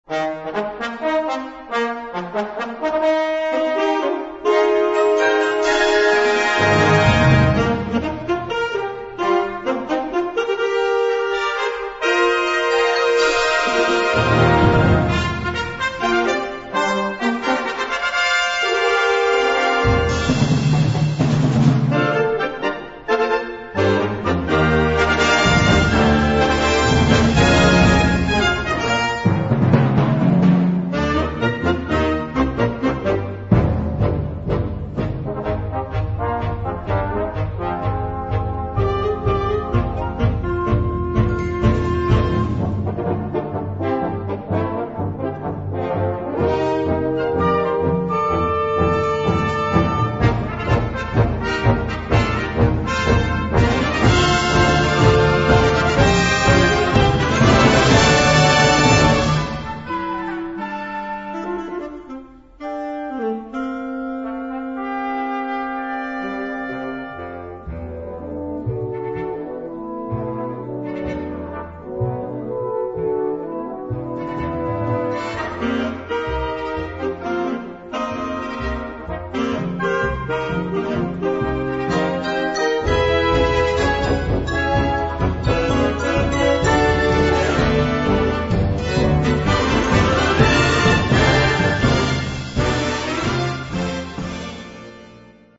Categorie Harmonie/Fanfare/Brass-orkest
Subcategorie Concertmuziek
Bezetting Ha (harmonieorkest); / (oder); Fa (fanfare)